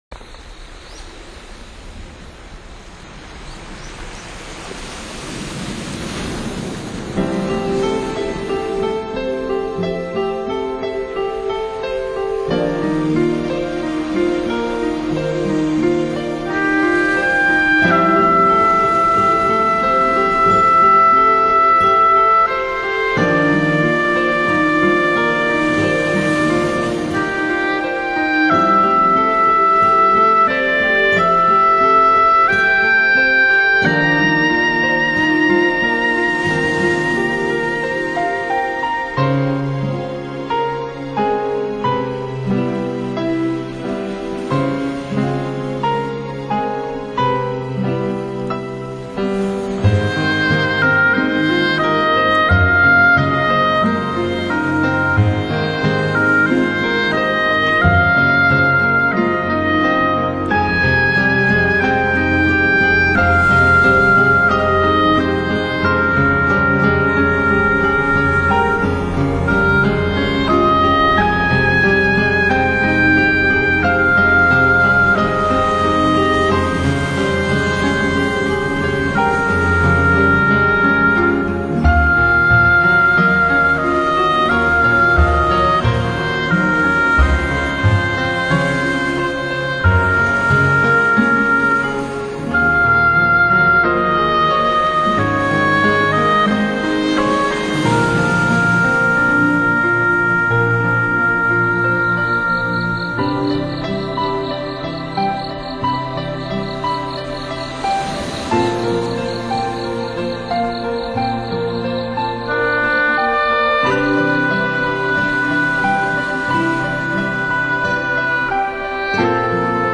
专辑语言：纯音乐
台湾沿海实地录音，临场海浪声绝无仅有。。
双簧管、英国管
曲笛
吉他
棕耳鹎、蟋蟀
在龟山岛上录音，夜色如水银般凝固。